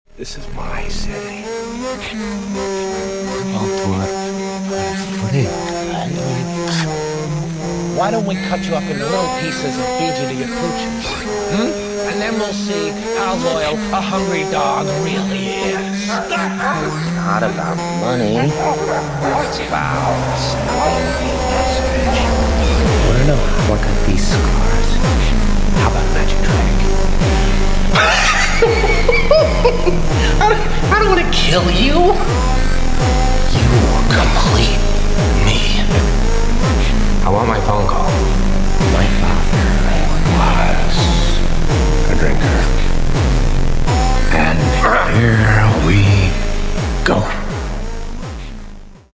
Hardstyle (slowed)